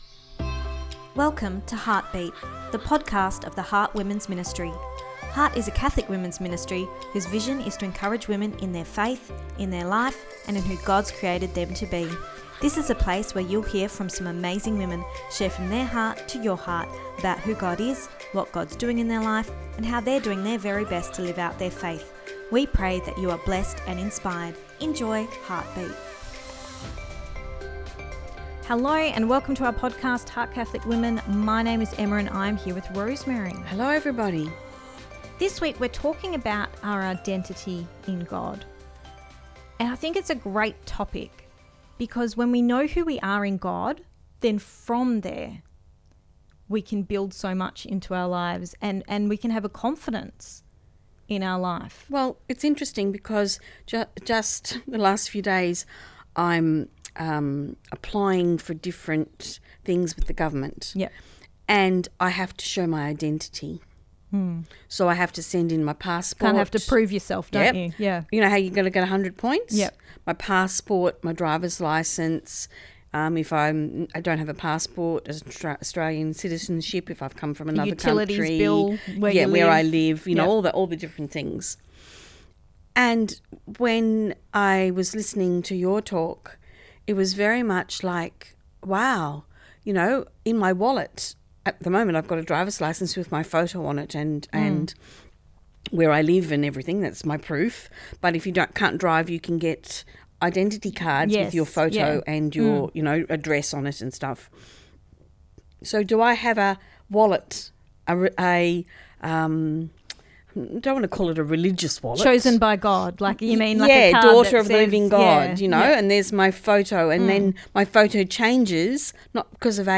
Ep265 Pt2 (Our Chat) – Who Are You Becoming